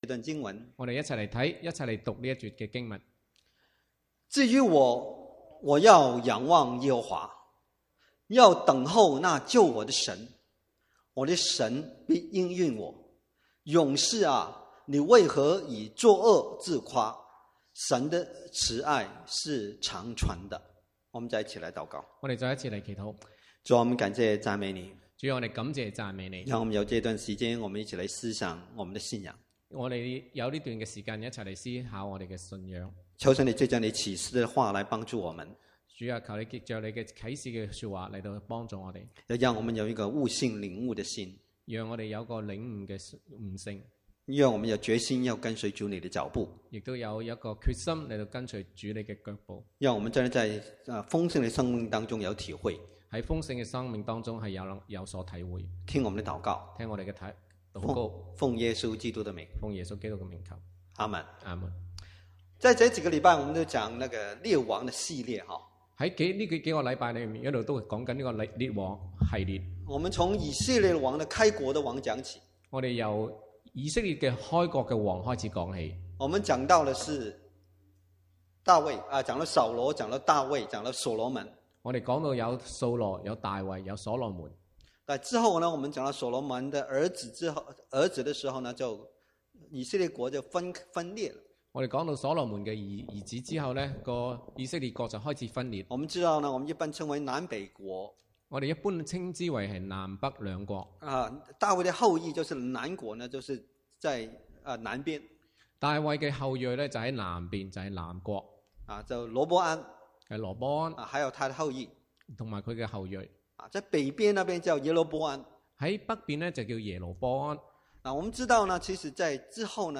From Series: "Chinese Sermons"